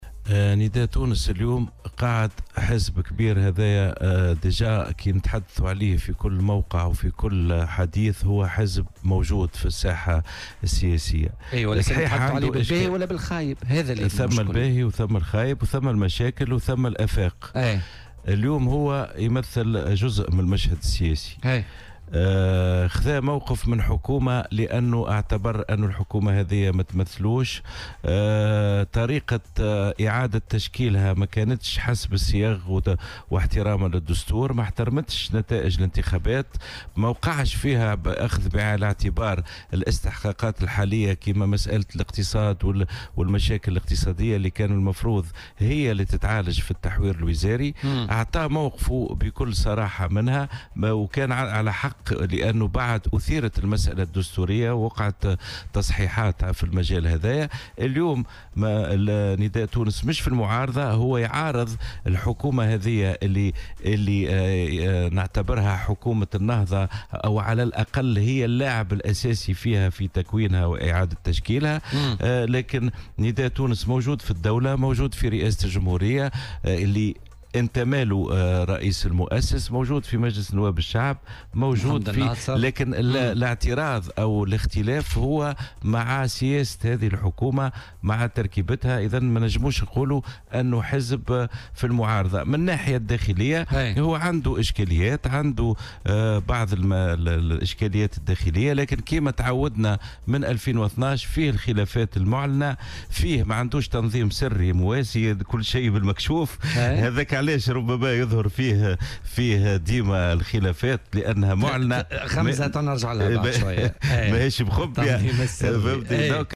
وأوضح في مداخلة له اليوم في برنامج "بوليتيكا" إن للحزب موقف من "حكومة النهضة" وسياستها وتركيبتها، لكن لا يمكن القول بأنه حزب يقبع في المعارضة.